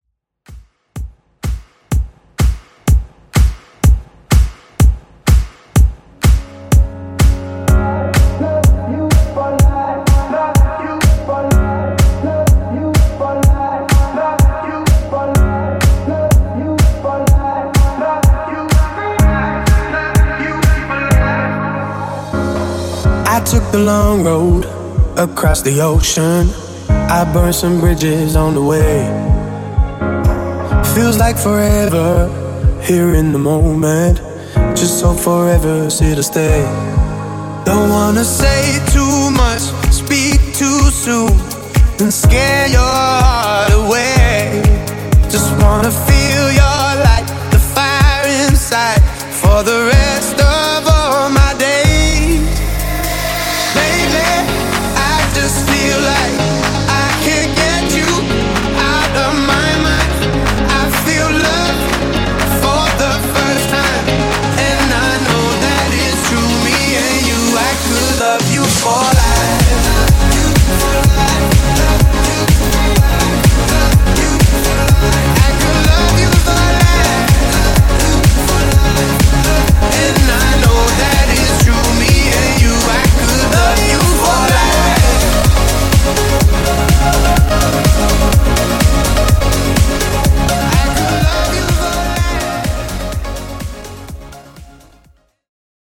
Club Extended)Date Added